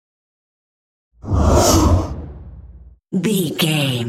Whoosh fast trailer
Sound Effects
Fast
futuristic
intense
whoosh